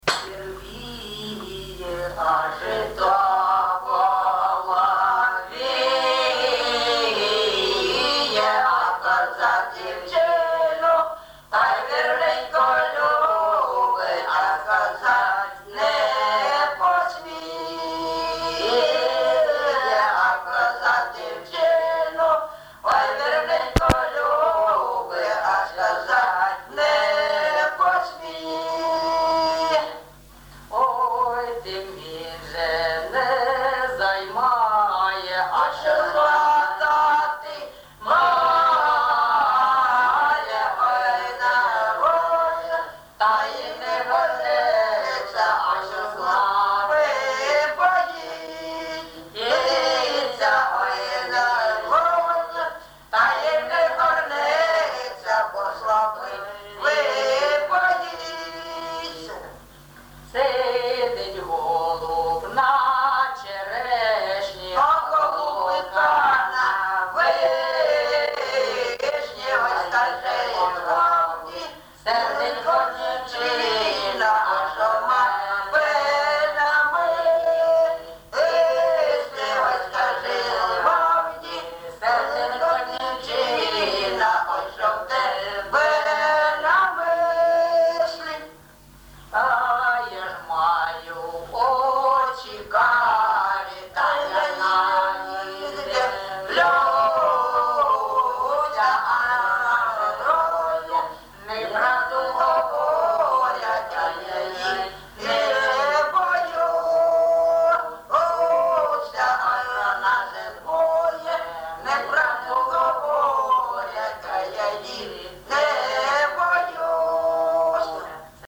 ЖанрПісні з особистого та родинного життя
Місце записум. Єнакієве, Горлівський район, Донецька обл., Україна, Слобожанщина